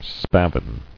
[spav·in]